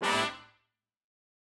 GS_HornStab-Amin+9sus4.wav